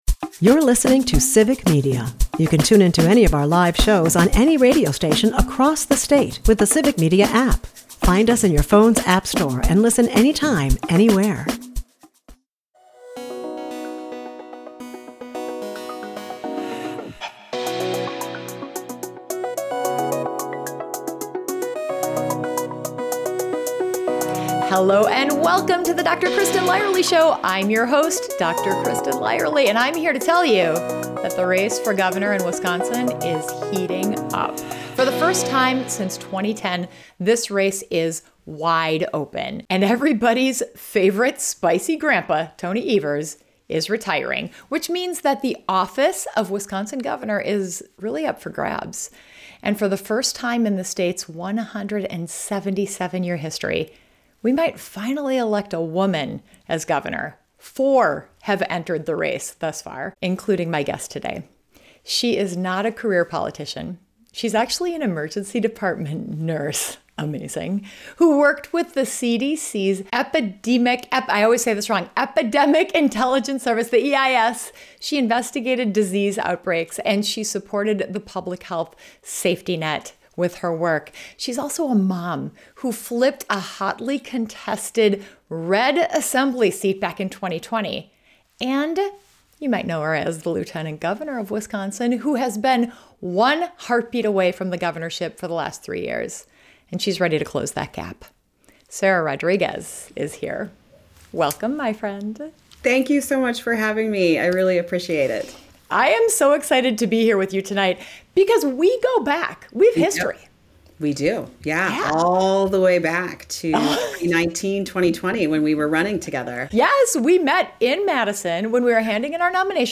From their shared experience as healthcare professionals entering politics during the pandemic to the fight for Medicaid expansion and reproductive rights, this conversation explores what it takes to lead boldly in uncertain times. Sara shares her vision for Wisconsin's future and why healthcare expertise matters in the governor's office.